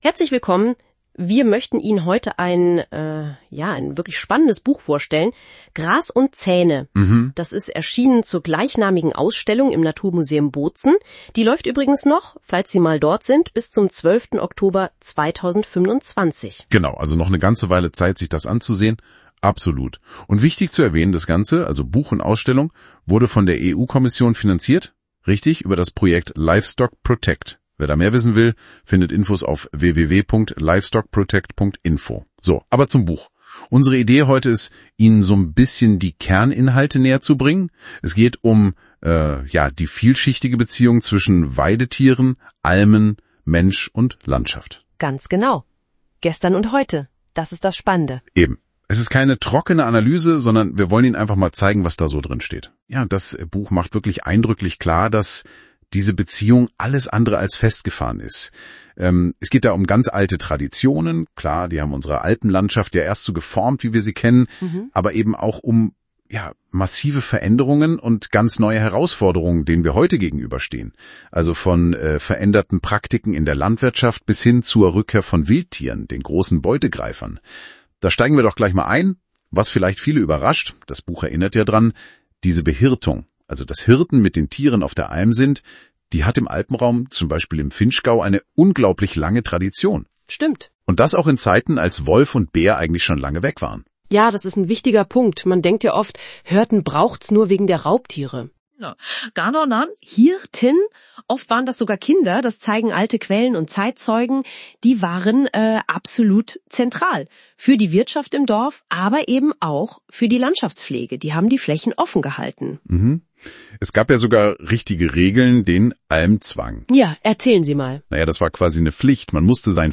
Buchvorstellung im Podcast der European Wilderness Society (EWS)
LIFEstockprotect-Podcast-Buchvorstellung-Gras-und-Zaehne.mp3